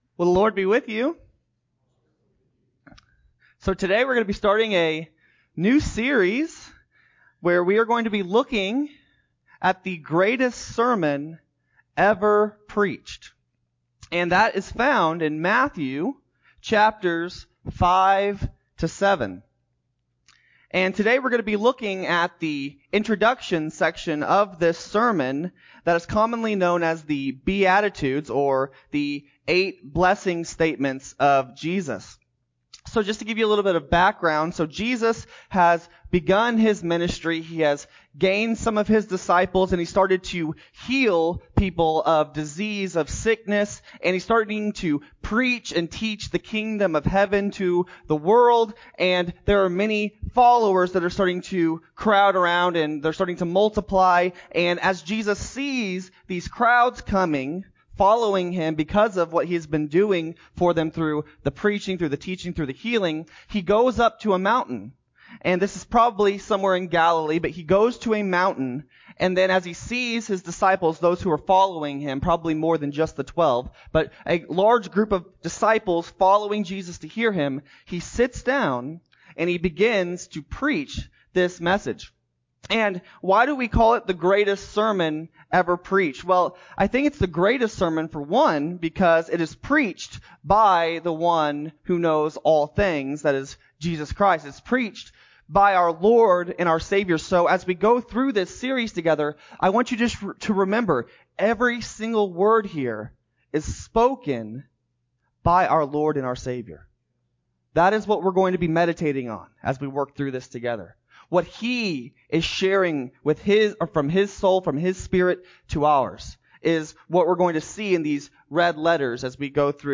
7-25-21-Sermon-CD.mp3